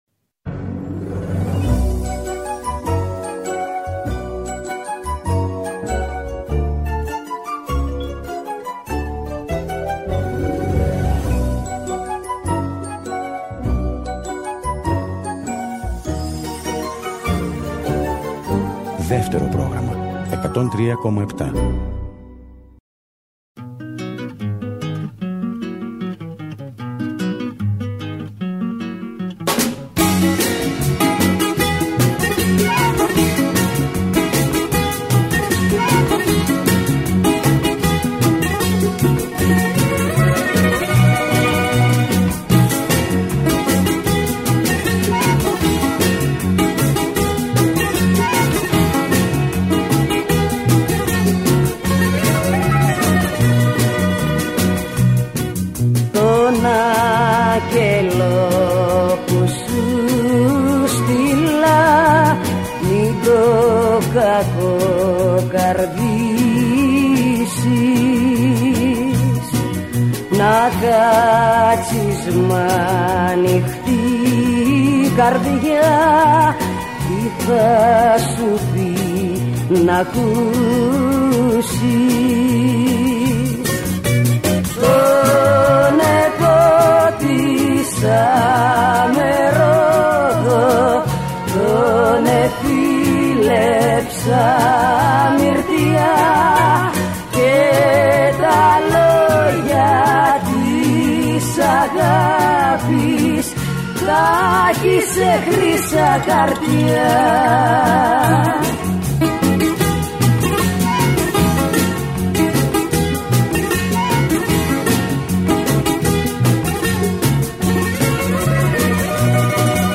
Τί καλύτερο για το Σαββατόβραδο από μια εκπομπή με τραγούδια που αγαπήσαμε;